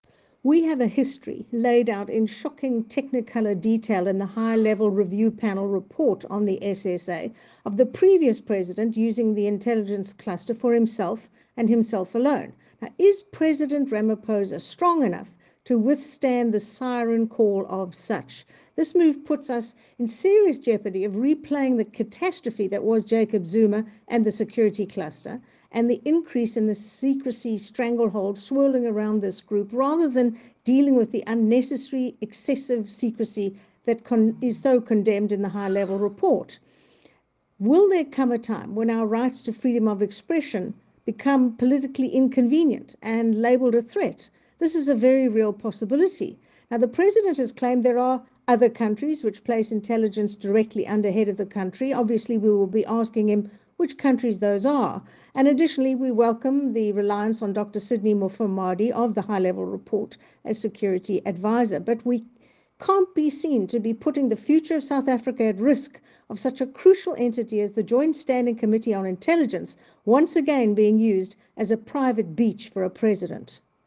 Issued by Dianne Kohler Barnard MP – Spokesperson on National Intelligence
soundbite by Dianne Kohler Barnard MP.